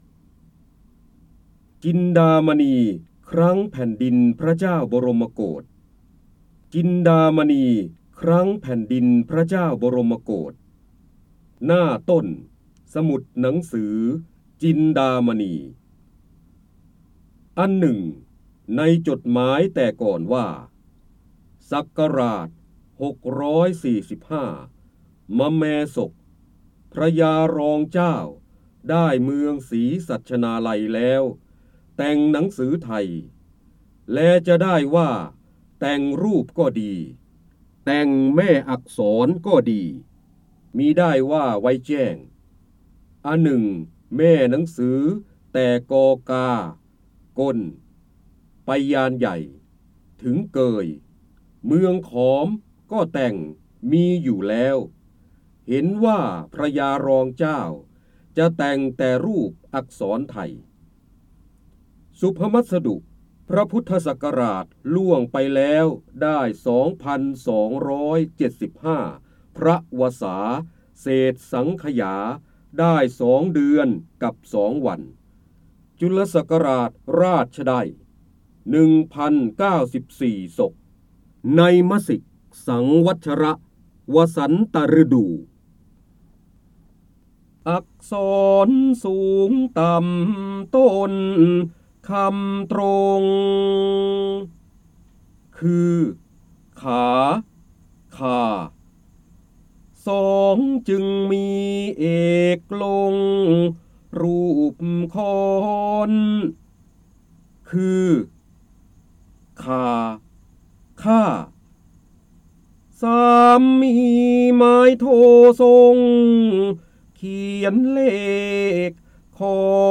เสียงบรรยายจากหนังสือ จินดามณี (พระเจ้าบรมโกศ) จินดามณีครั้งแผนดินพระเจ้าบรมโกศ